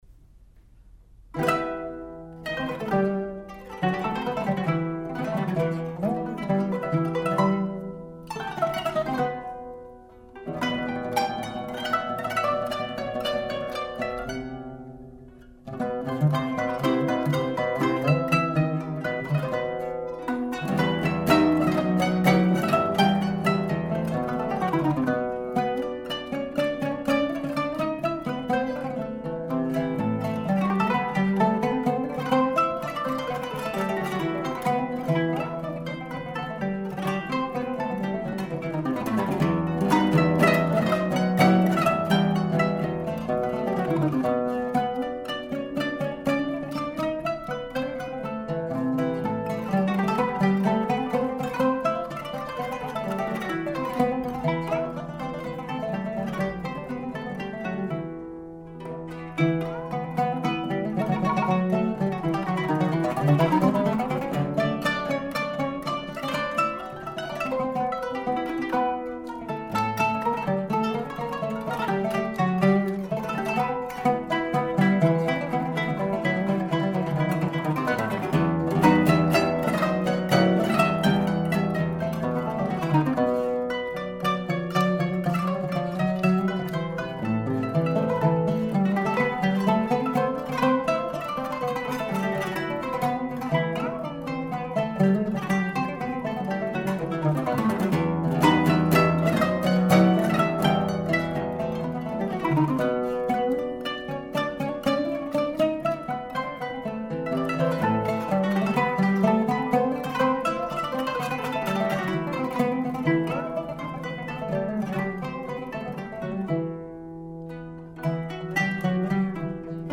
kanun
oud